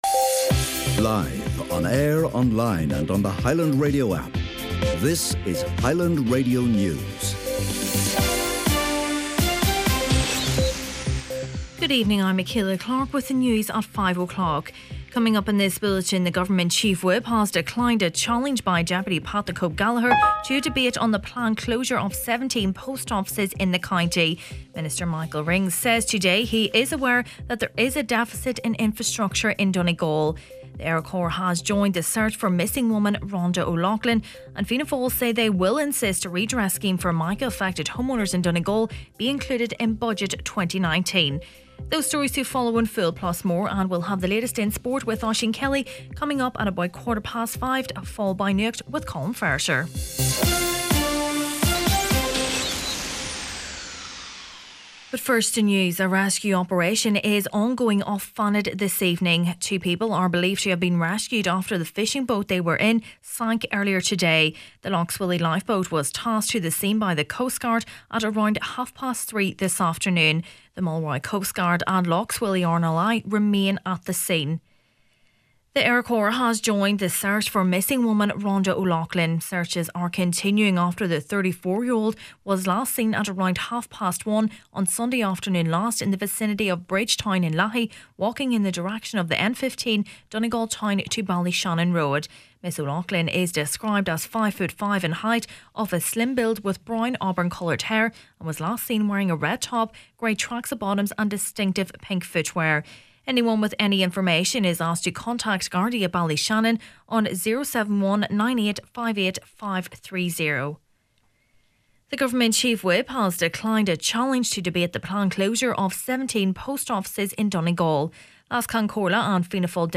Main Evening News, Sport, Obituaries and Nuacht Friday September 28th